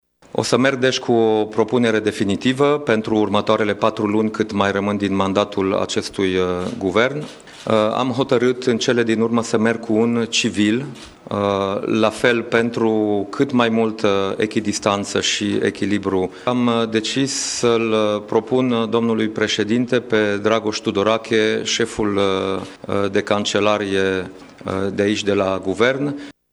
Dragoș Tudorache, în prezent șeful Secretariatului General al Guvernului, va prelua portofoliul ministerului pentru următoarele 4 luni, a precizat premierul Dacian Ciolos: